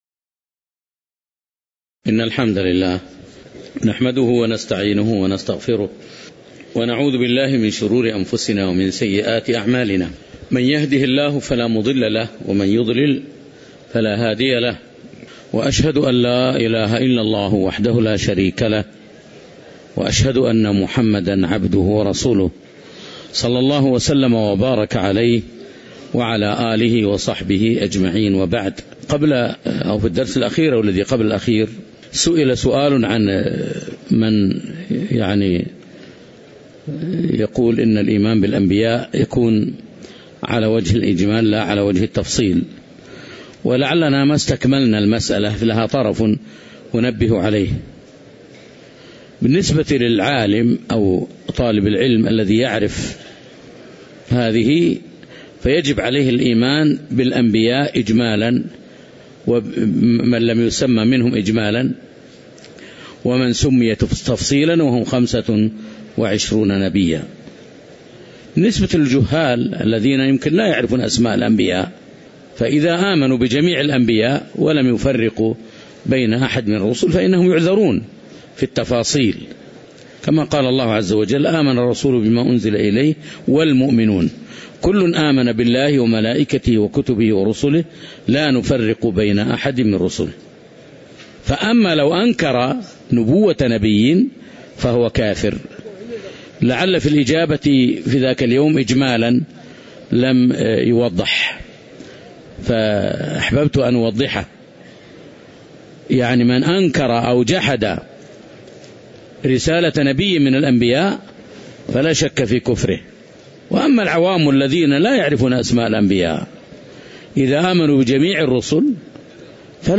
تاريخ النشر ٤ ربيع الأول ١٤٣٩ هـ المكان: المسجد النبوي الشيخ